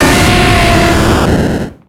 Cri de Typhlosion dans Pokémon X et Y.